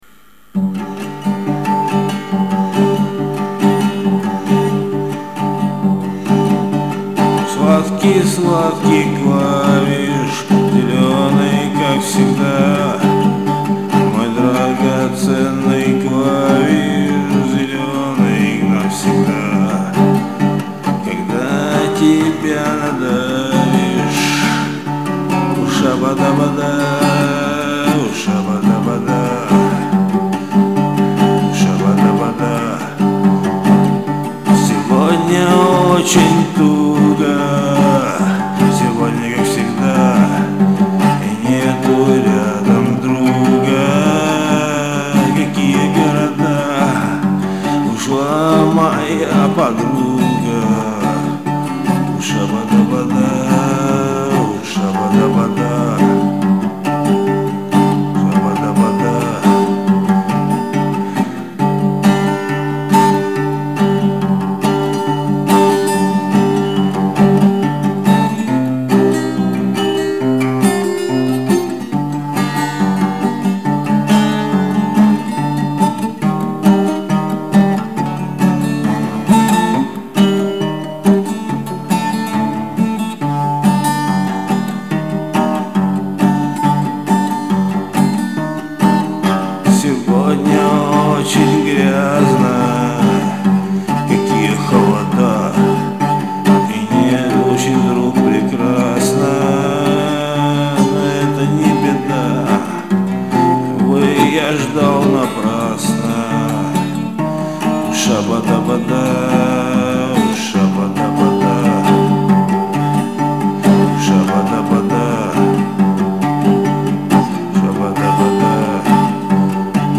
напел недавно cover